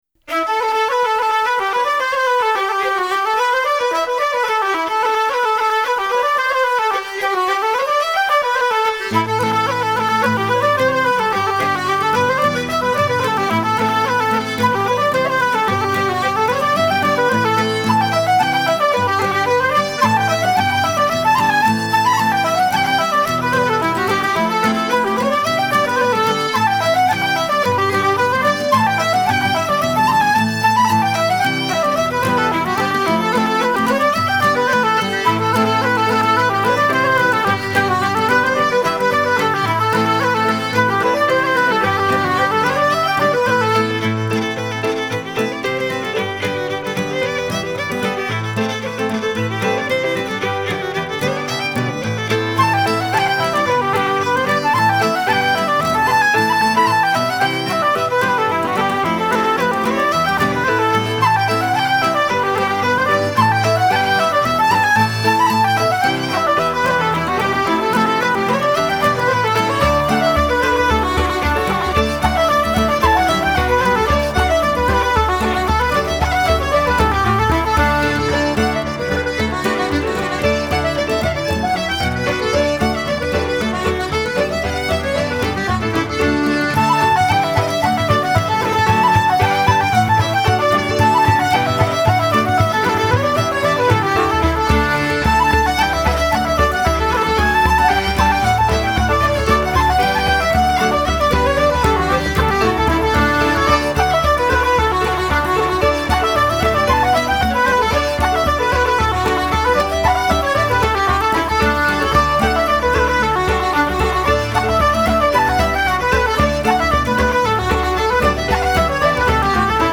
流派：Folk
风笛是一种很特别的乐器，就像画眉鸟的嗓子。
风笛倾诉着流浪和寂寞，流露出纯洁而洒脱的感情，干净而飘，苍凉而远，那声音纯净得不似来自人间。
配合小提琴、手风琴、新纪元琴键、结他、竖琴，风笛及大型弦乐团